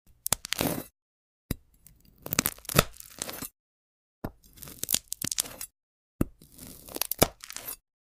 Soft cuts. Soft sound. Pure sound effects free download
She cuts the jelly… and your brain melts. No talking. Just satisfying sound and AI visuals.